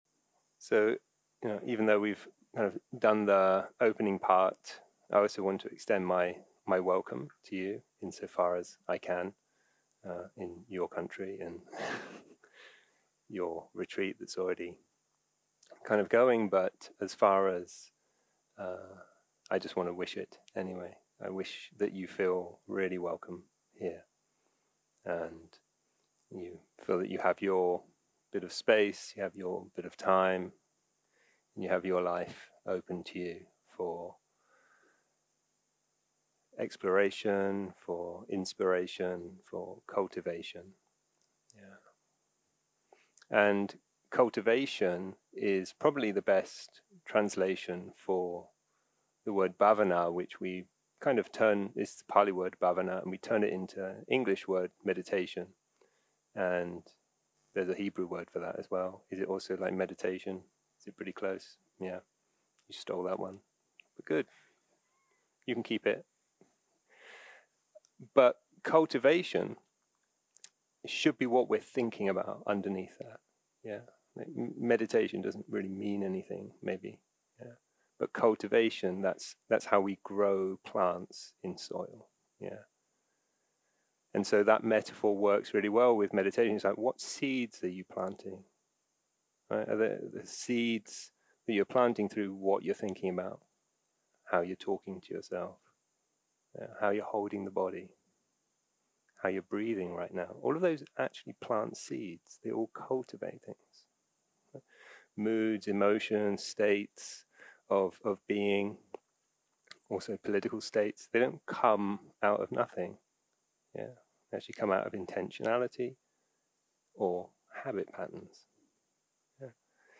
יום 1 - הקלטה 1 - ערב - שיחת דהרמה - For the benefit of all
Dharma Talks שפת ההקלטה